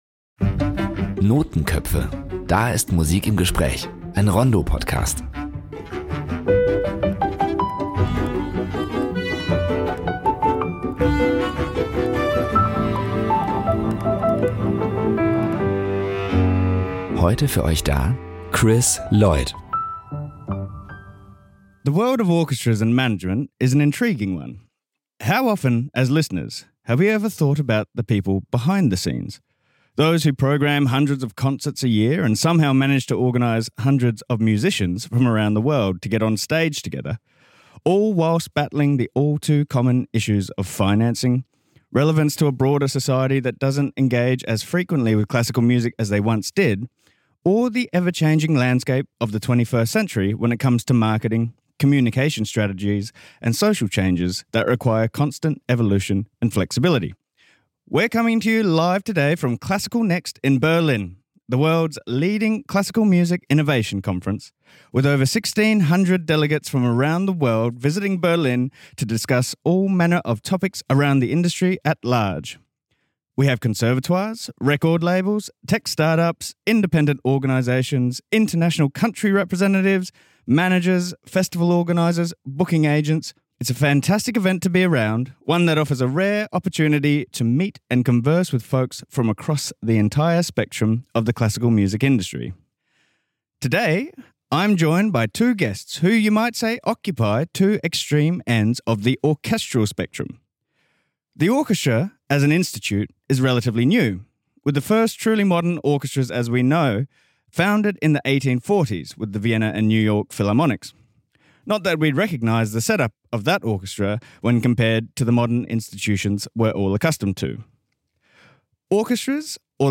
Beschreibung vor 4 Monaten Live from the conference for classical and art music Classical:NEXT in Berlin: In this “Notenköpfe” episode
Together they examine funding crises, audience shifts, diversity debates and the creative tension between tradition and innovation. A sharp, candid conversation from the centre of the international classical scene.